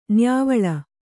♪ nyāvaḷa